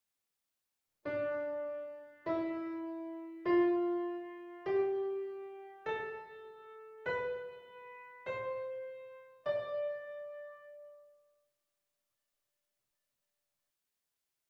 Dorian Mode
They are arranged as the following: T – S – T – T – T – S – T.
dorian-mode.mp3